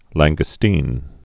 (lănggə-stēn)